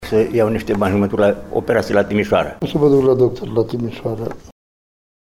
Pentru ce se împrumută am aflat chiar de la unii dintre cei prezenţi la ghişeele CARP pentru a ridica împrumuturile solicitate: